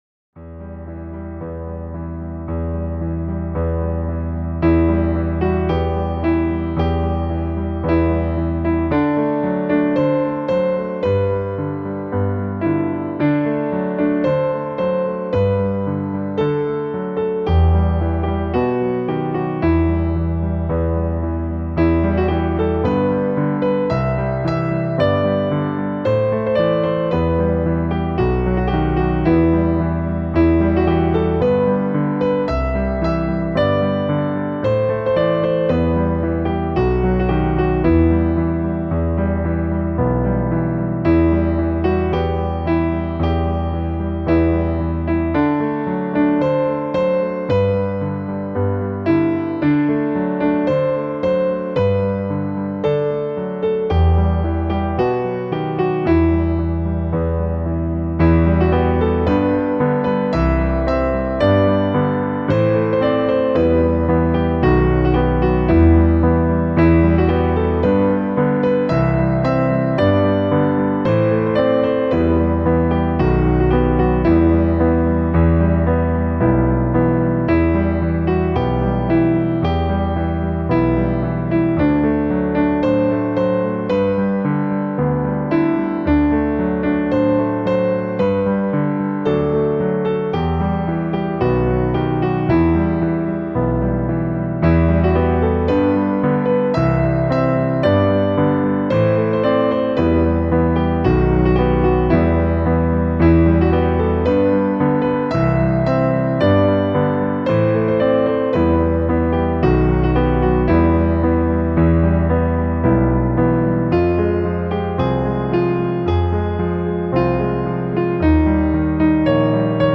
Piano-Interpretation